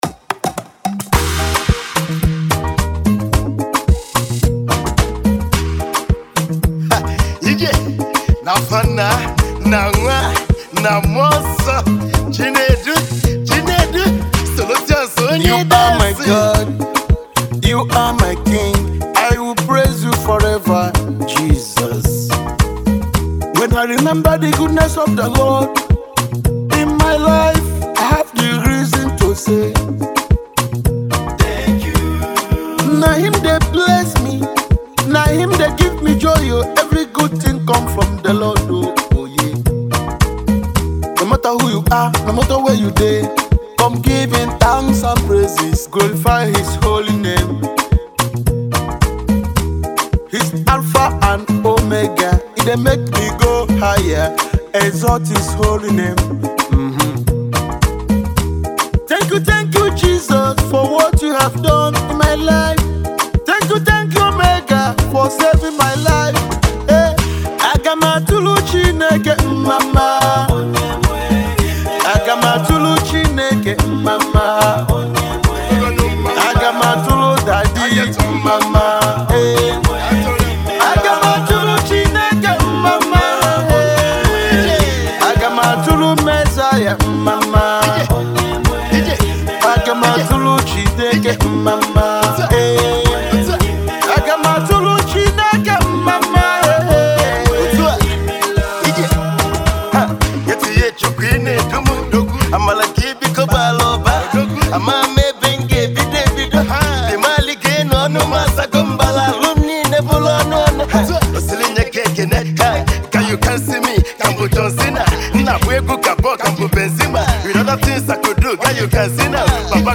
gospel pop singer